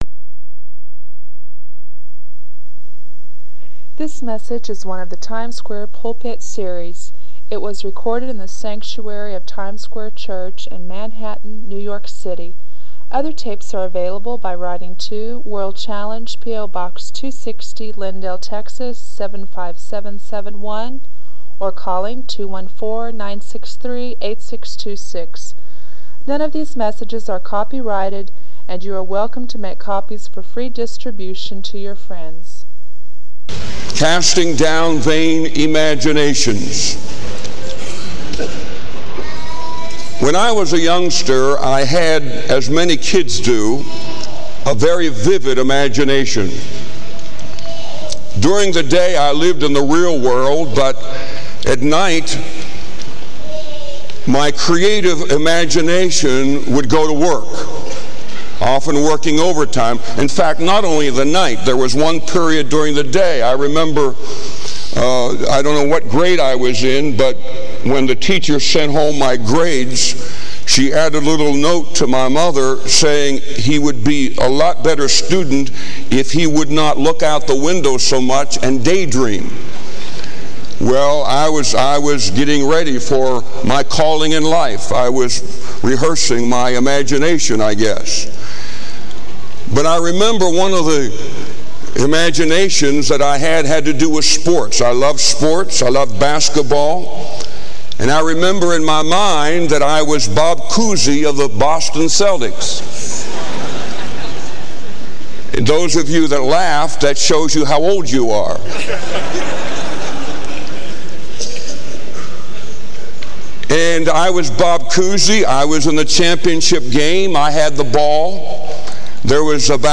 This sermon is a vital call to spiritual vigilance and godly discernment.